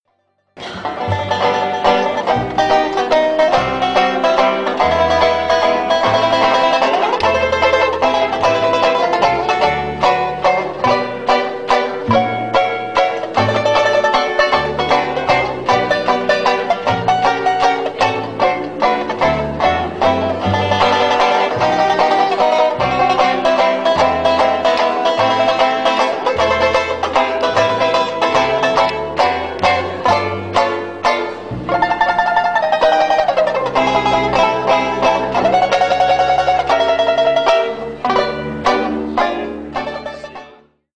12-beat intro.
This song is in 3/4 waltz time.